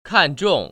[kàn//zhòng] 칸중